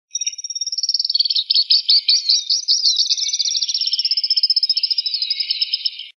Arredio (Cranioleuca pyrrhophia)
Nome em Inglês: Stripe-crowned Spinetail
Fase da vida: Adulto
Província / Departamento: Entre Ríos
Condição: Selvagem
Certeza: Fotografado, Gravado Vocal
Curutie-blanco-(2).mp3